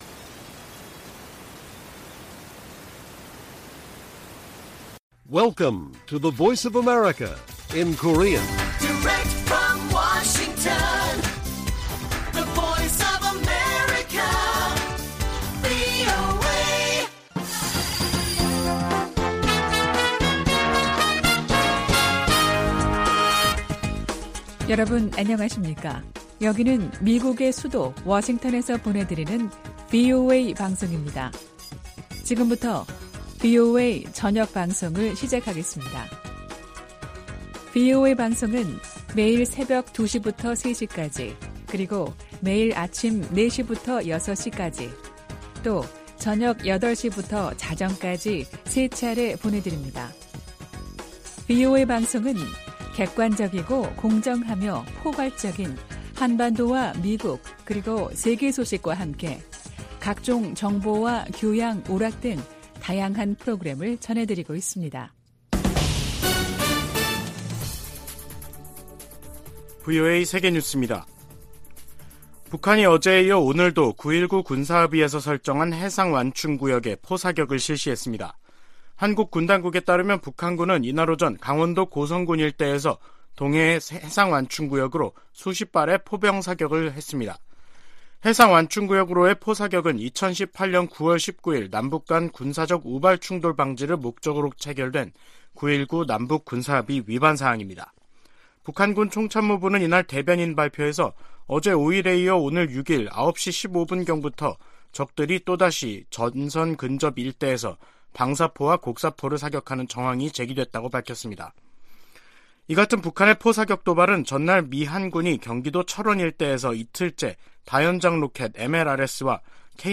VOA 한국어 간판 뉴스 프로그램 '뉴스 투데이', 2022년 12월 6일 1부 방송입니다. 북한 김정은 정권이 미군과 한국 군의 통상적인 훈련을 구실로 이틀 연속 9.19 남북 군사합의를 위반하며 해상완충구역을 향해 포 사격을 가했습니다. 중국이 미중 정상회담 이후에도 여전히 북한 문제와 관련해 바람직한 역할을 하지 않고 있다고 백악관 고위 관리가 지적했습니다.